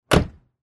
Звук захлопнутой двери автомобиля